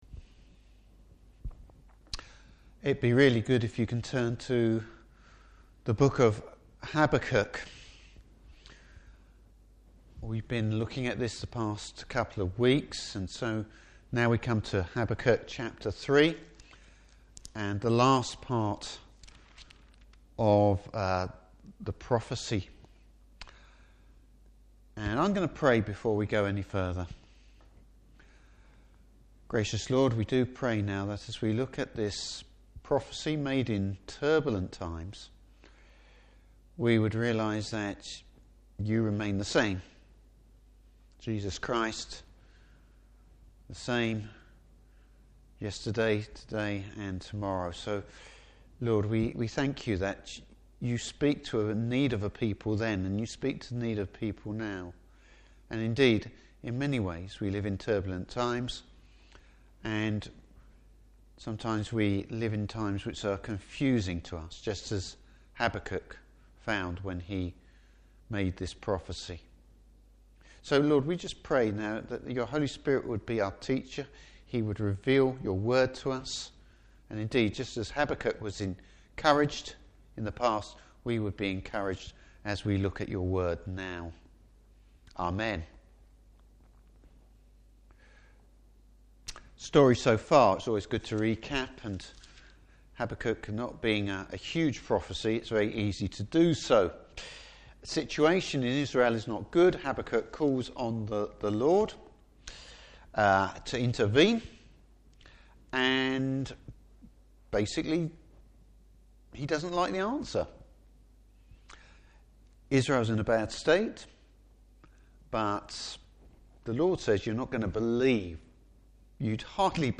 Service Type: Evening Service Habakkuk praises the Lord as he now has greater understanding.